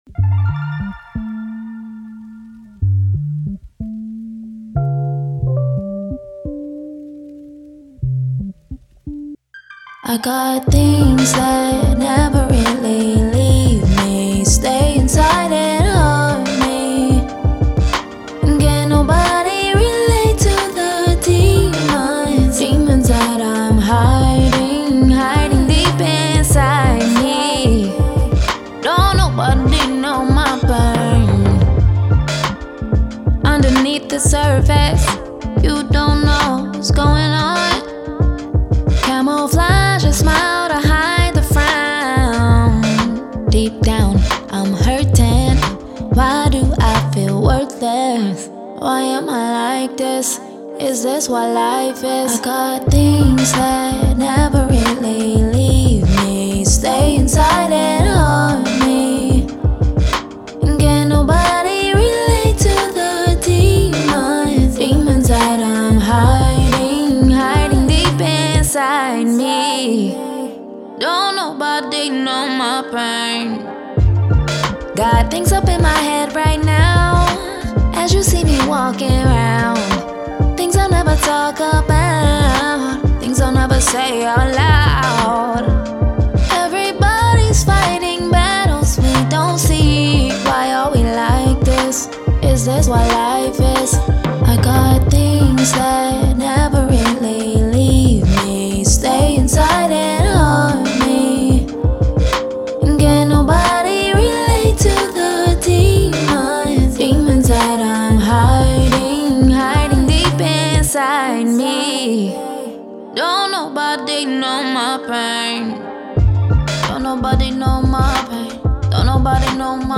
R&B
G Minor